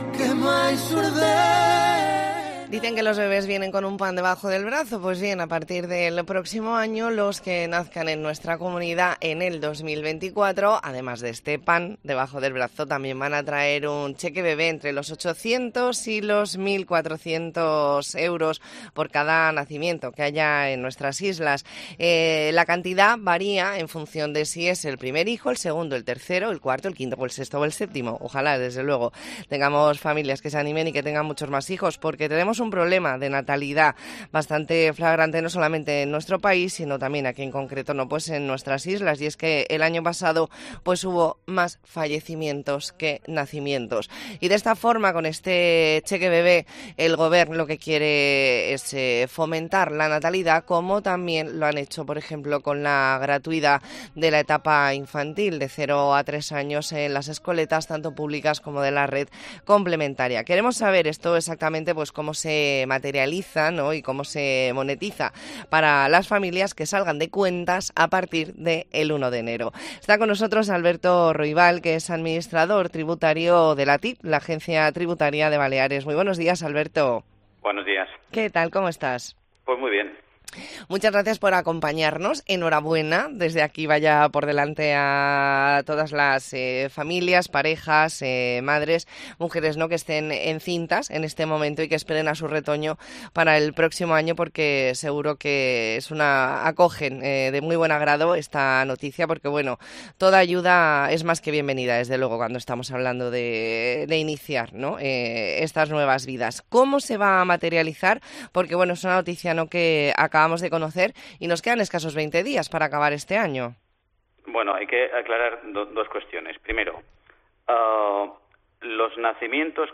Entrevista en La Mañana en COPE Más Mallorca, lunes 11 de diciembre de 2023.